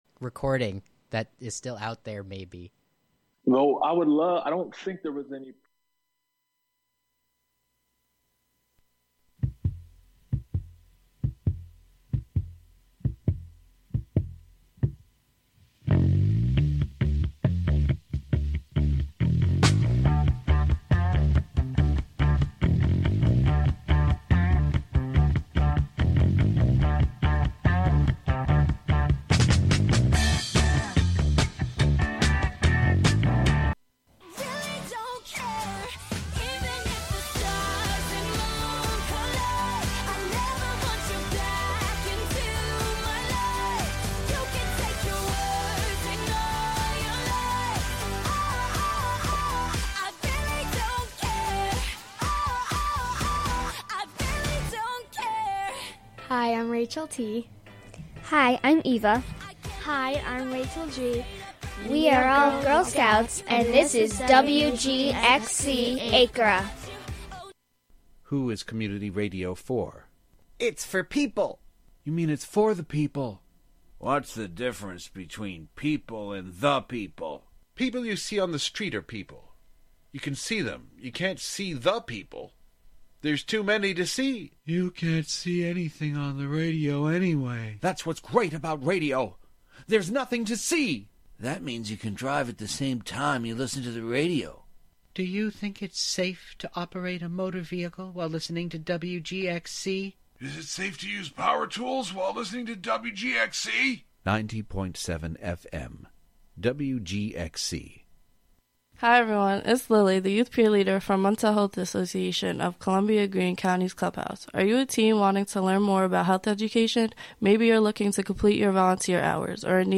"Auditions" features prospective WGXC volunteer programmers trying out their proposed radio programs on air for listener feedback.